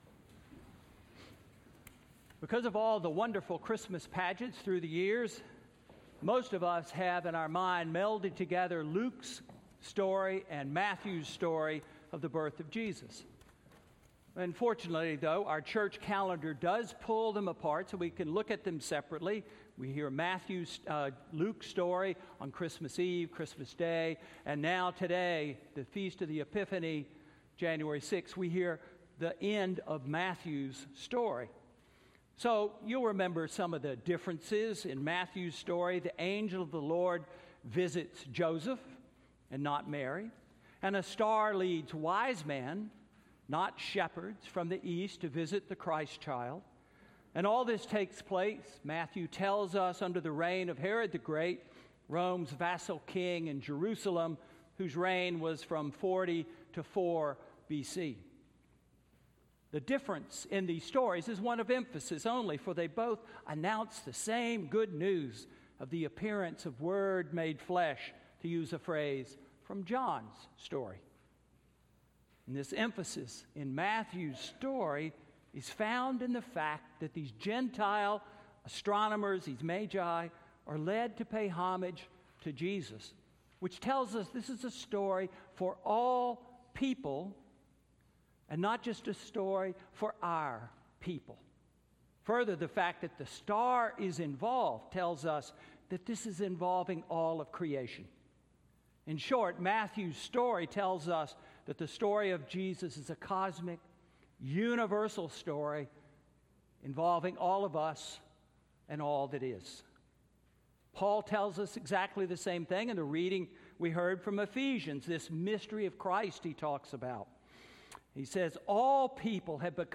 Sermon–Who shrunk our faith?